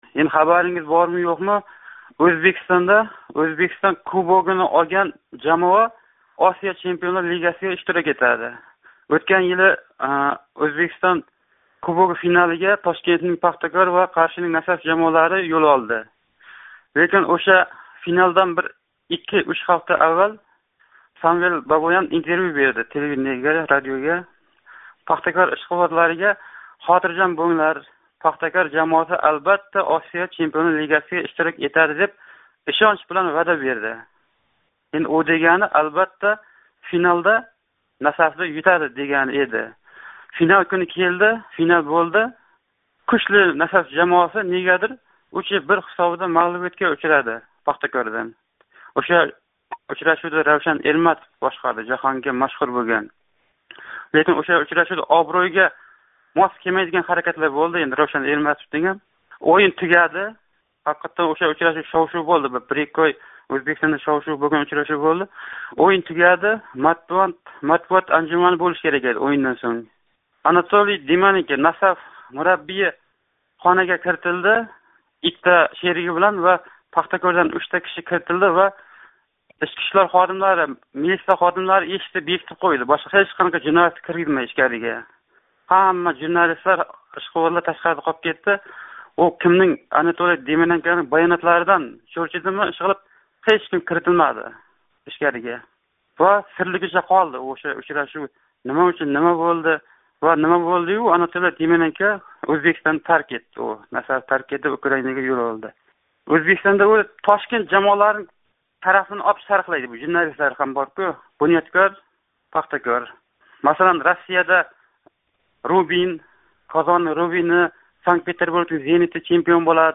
"Эркин микрофон"га қўнғироқ қилган тингловчиларимиз ҳар доимгидек ўз атрофидаги муаммолардан гапирди. Айримлари эса Озодликда ёритилаётоган мавзулар ҳақида ўз фикрларини билдирди.